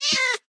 / assets / minecraft / sounds / mob / cat / hitt1.ogg